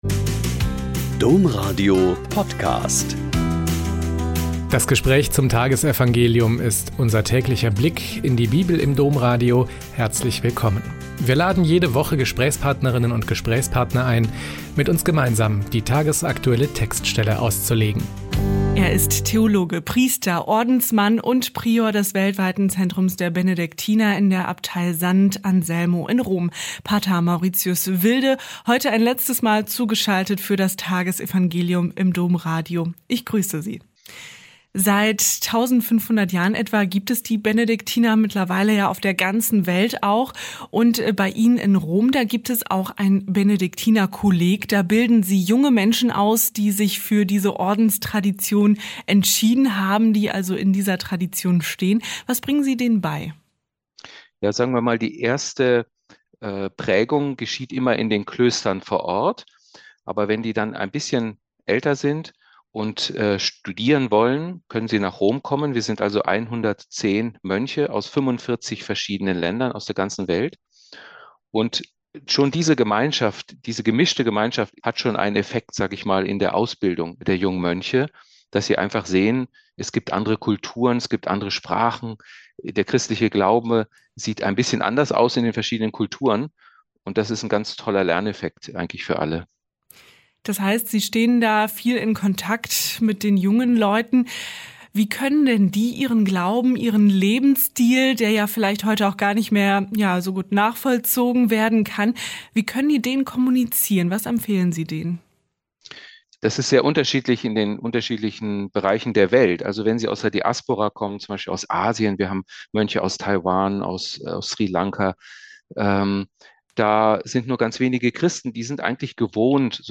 Lk 20,27-40 - Gespräch